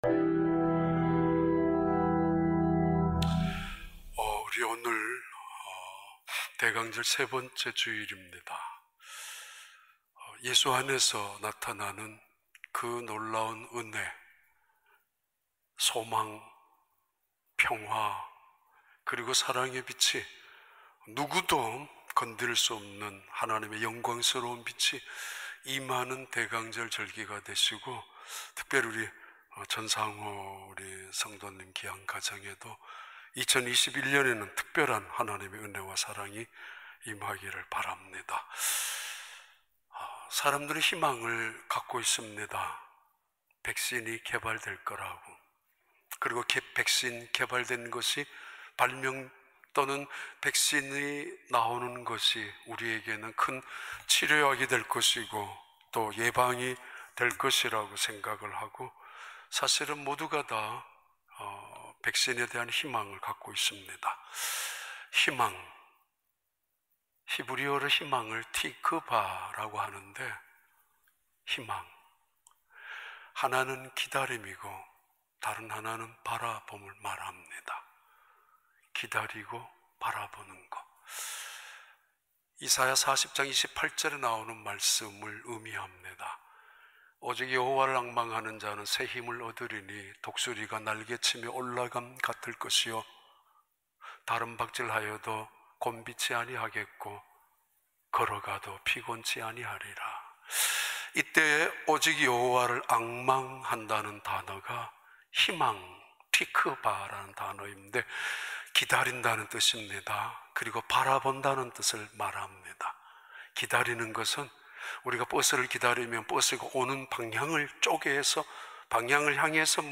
2020년 12월 13일 주일 4부 예배